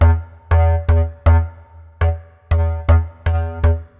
loops basses dance 120 - 2
Basse dance 6 D